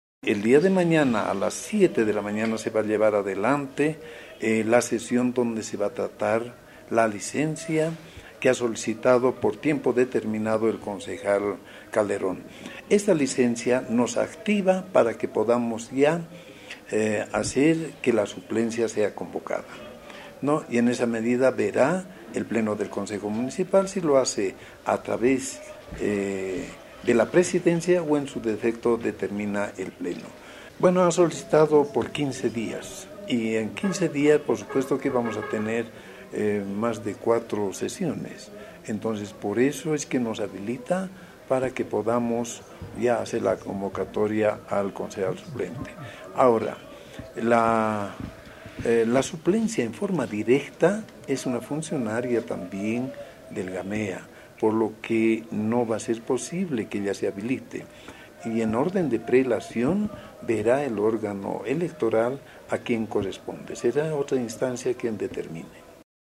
AUDIO: Antioco Cala, presidente del Concejo Municipal de El Alto